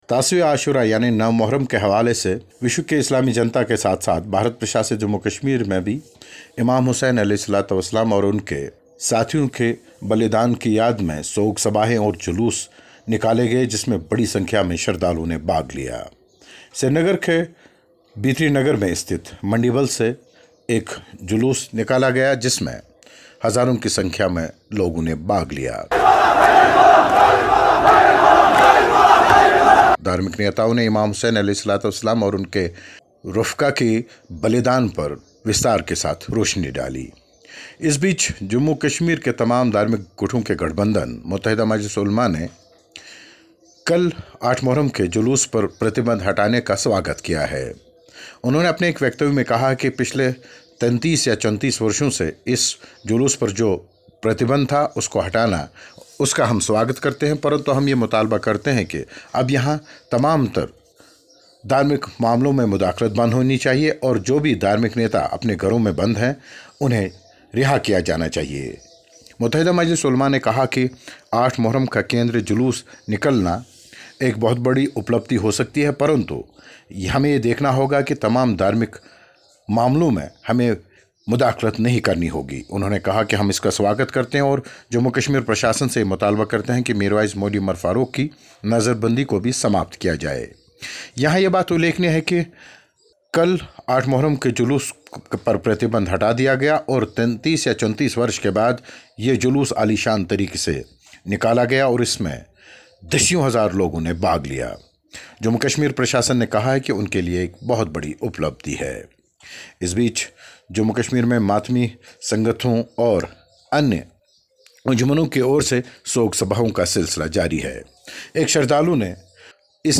3 दशक बाद कश्मीर में मोहर्रम का ऐतिहासिक जुलूस हुआ बरामद, रिपोर्ट